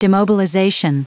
Prononciation du mot demobilization en anglais (fichier audio)
Prononciation du mot : demobilization